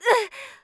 client / bin / pack / Sound / sound / pc / assassin / voice / damage_1.wav
damage_1.wav